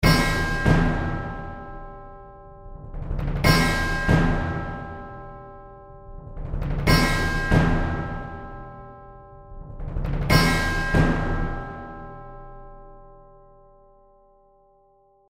And finally, the drums:
OrchestralHits_Drums.mp3